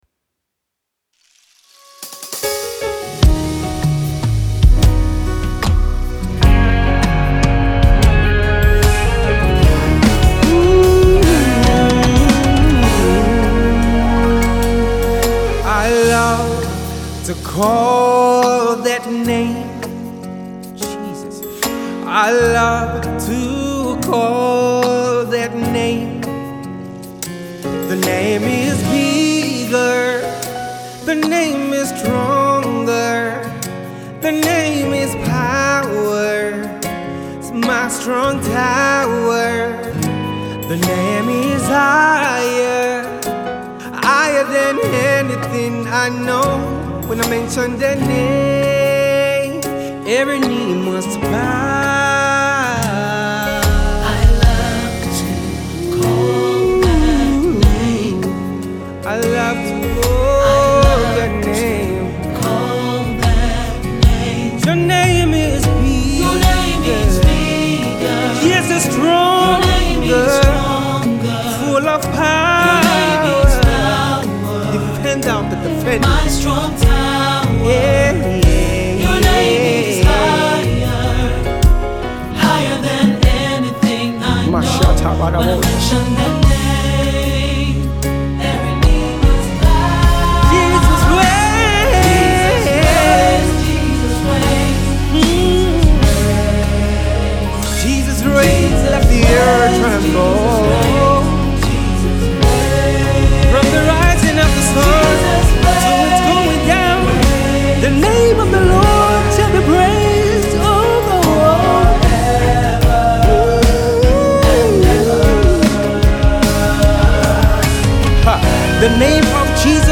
Gospel
soul and R&B